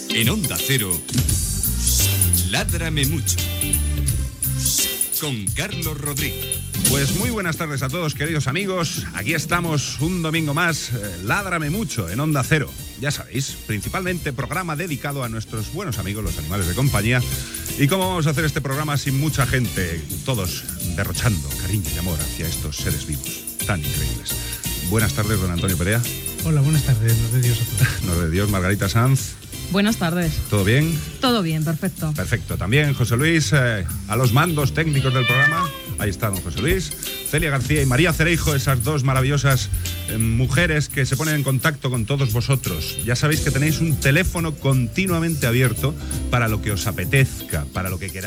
Careta, presentació i inici del programa dedicat als animals de companyia, crèdits.
Divulgació